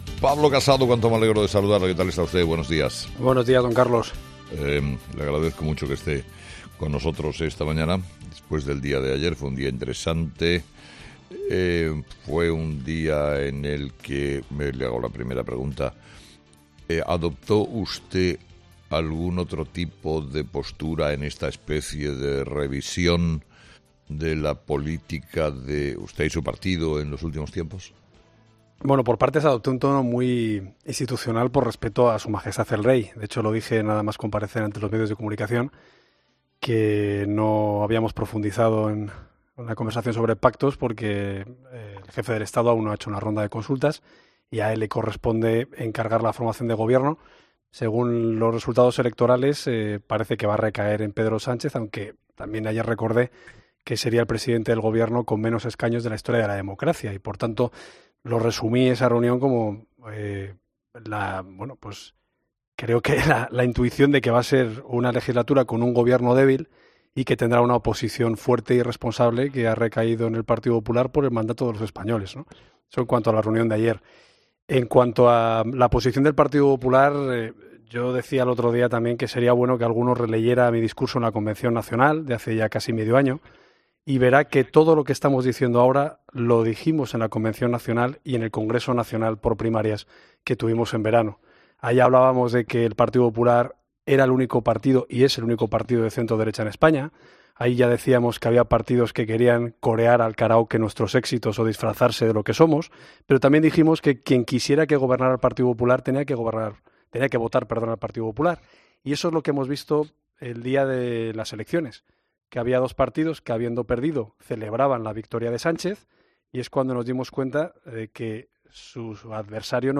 Casado invita en COPE a Cs a facilitar la investidura de Sánchez porque "no descartan pactos" tras el 26-M